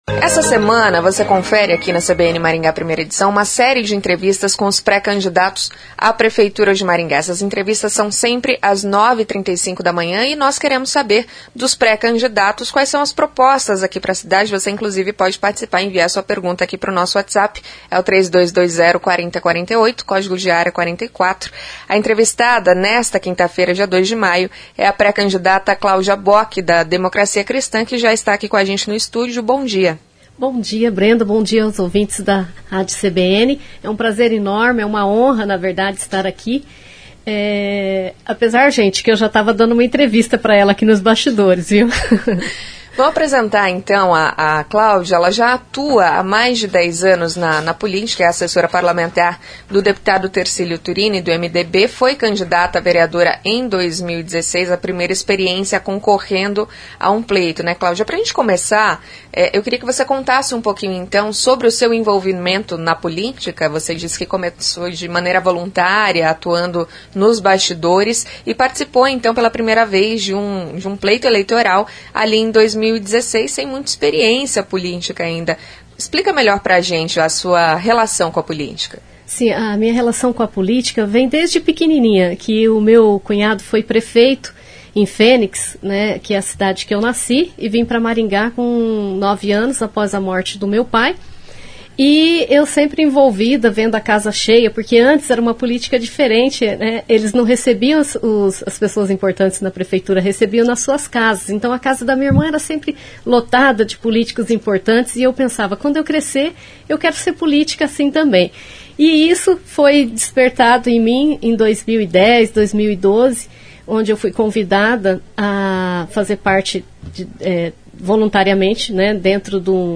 O Grupo Maringá de Comunicação (GMC) está realizando uma série de entrevistas com pré-candidatos à Prefeitura de Maringá nas rádios CBN Maringá e Maringá FM e no portal GMC Online.